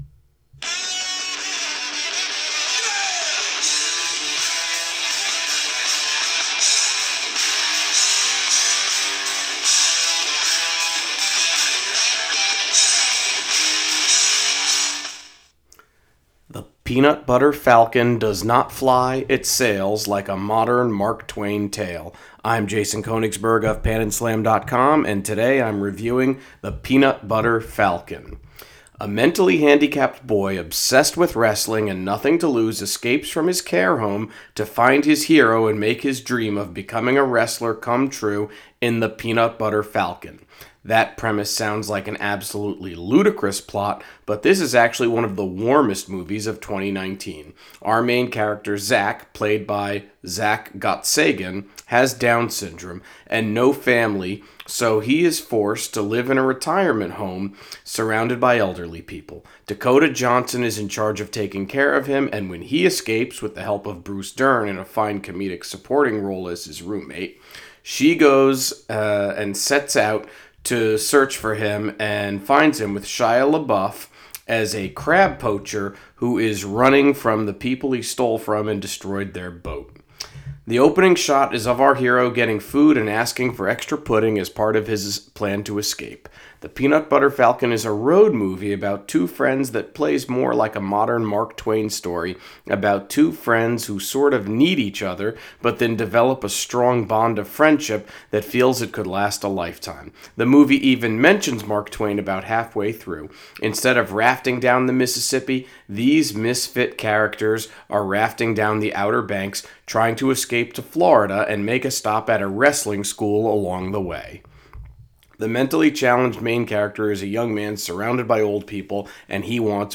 Movie Review: The Peanut Butter Falcon